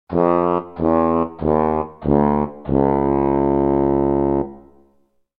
Sad Trumpet Fail Sound Effect
Sad-trumpet-fail-sound-effect.mp3